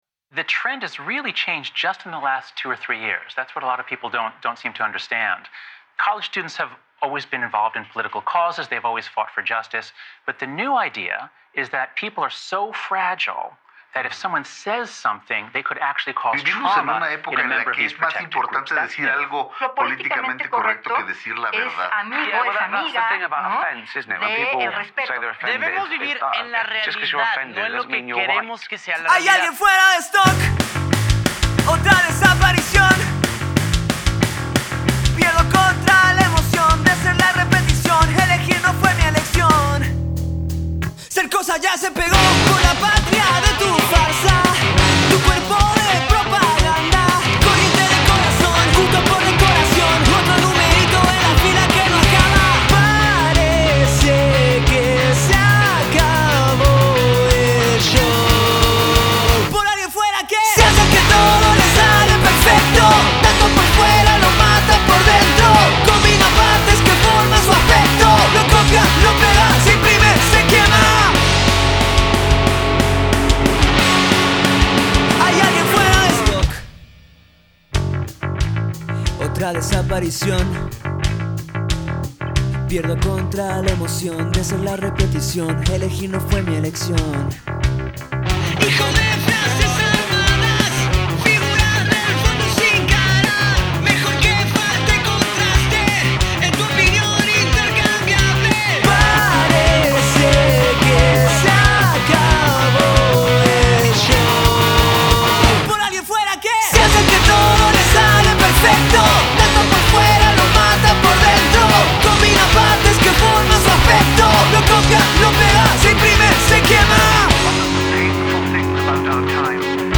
Set Eléctrico
Guitarra y Voz
Bajo y Coros
Batería y Coros
Set acústico
guitarra Acústica y Voz
Cajón Peruano y Coros